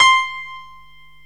PIANO 0006.wav